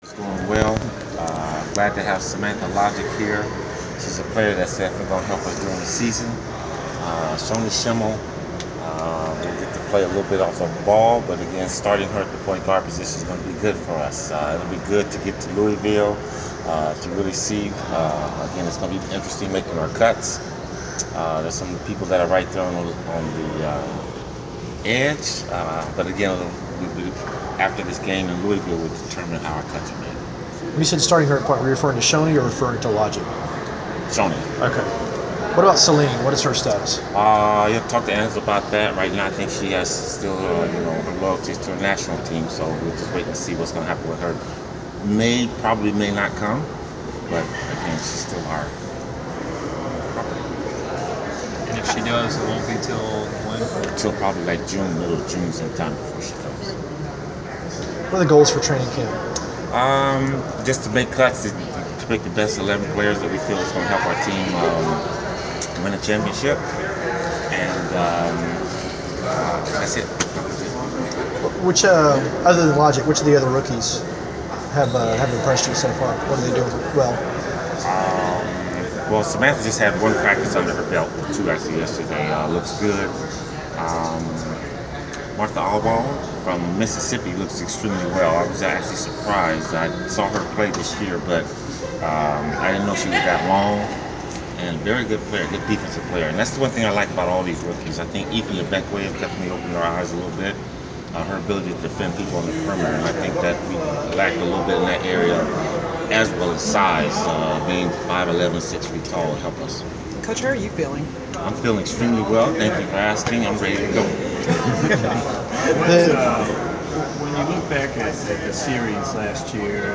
Inside the Inquirer: Interview with Atlanta Dream head coach Michael Cooper
The Sports Inquirer attended the presser of Atlanta Dream head coach Michael Cooper during his team’s media day festivities earlier this week. Topics included the early impressions of training camp, participating in events at Louisville University and the overall makeup of the team.